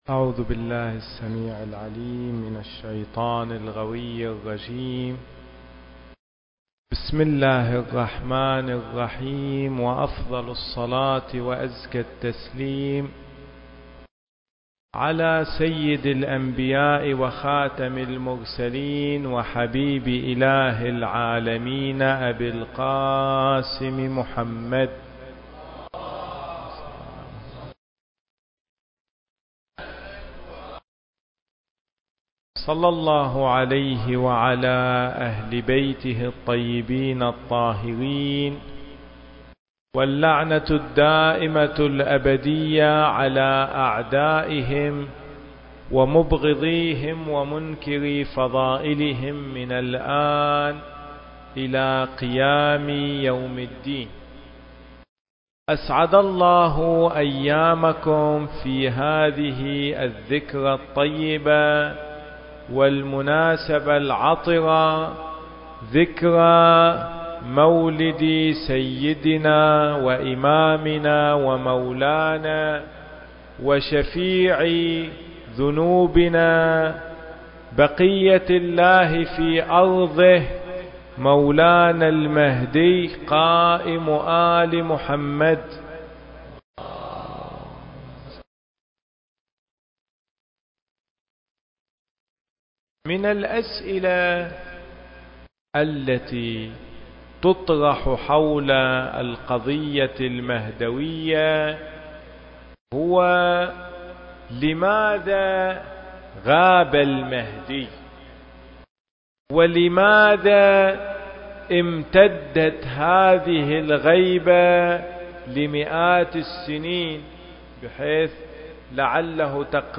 المكان: مسجد الإمام الحسين (عليه السلام) - صفوى التاريخ: 1436 للهجرة